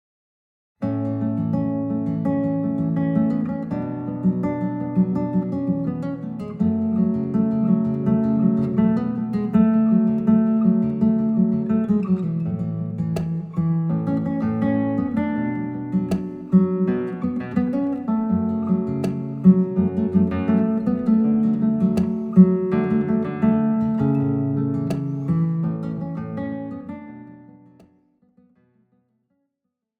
Besetzung: Gitarre